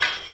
Perc (Tipoff - High).wav